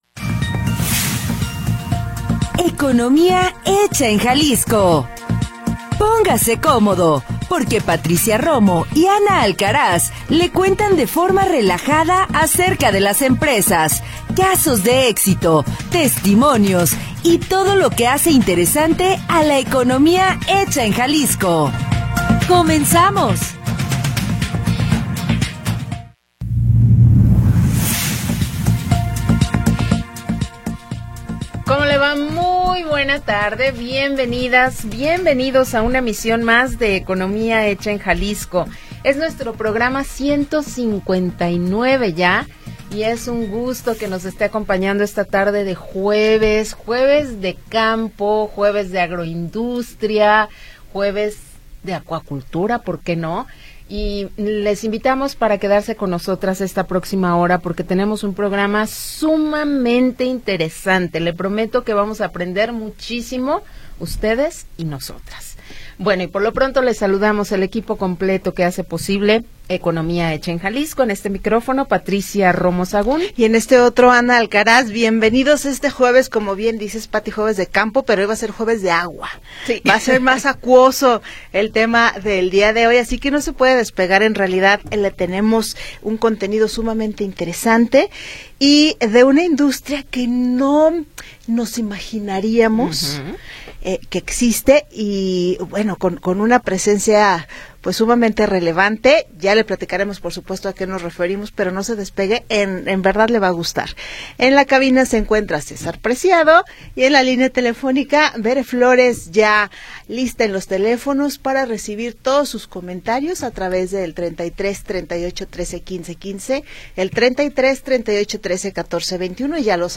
le cuentan de forma relajada acerca de las empresas, casos de éxito, testimonios y todo lo que hace interesante a la economía hecha en Jalisco. Programa transmitido el 9 de Octubre de 2025.